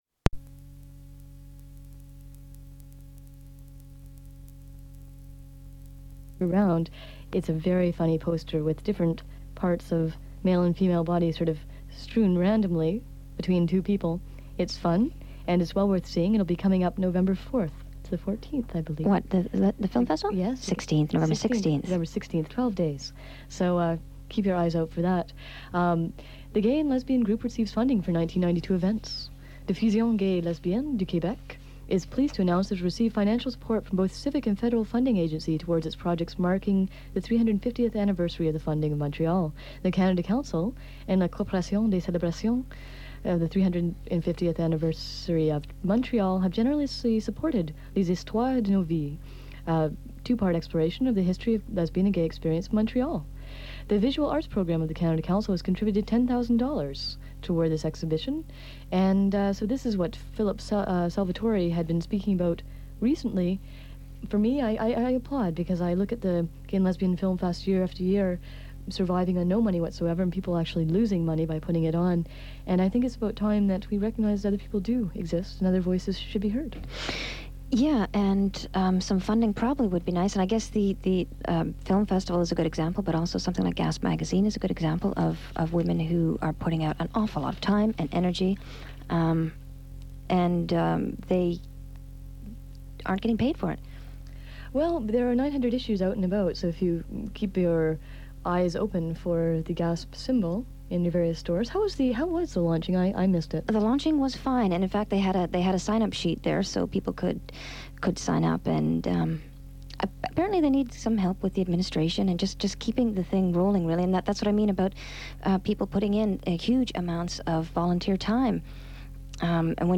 They discuss New Age culture and Indigenous appropriation, and Los Angeles based “plastic shaman” Lynn V. Andrews. This recording may have been taped over a previous interview, and cuts in and out of a recording on disco music.
The Dykes on Mykes radio show was established in 1987.